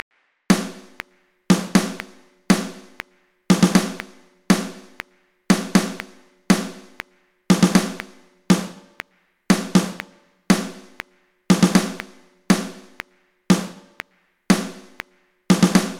To complete this pattern for a drum set, you will often hear claps/tambourine/snare on beats 2 and 4 and a steady 8th note or 16th note rhythm in the high hat (sometimes doubled in maracas or other percussion instruments, a variation on guitar, or a higher synth/piano part).
Classic Rock SD
Classic-Rock-SD.mp3